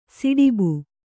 ♪ siḍibu